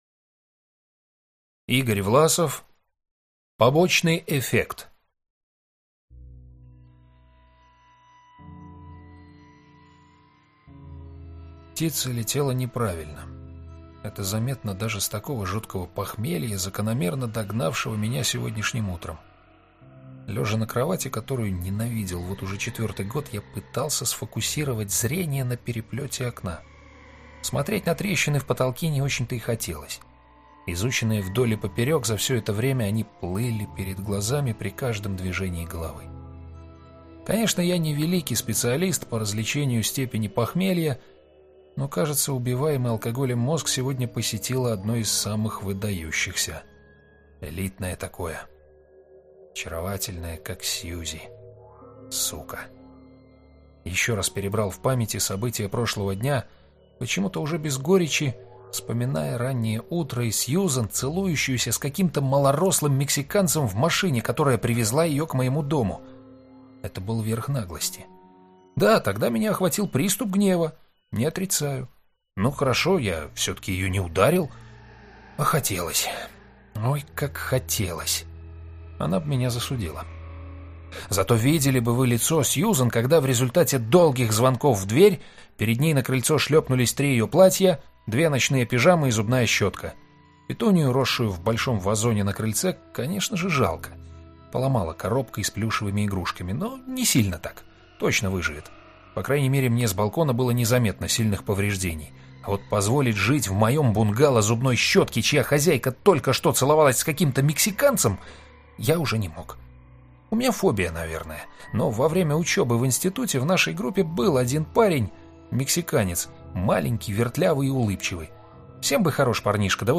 Аудиокнига Побочный эффект | Библиотека аудиокниг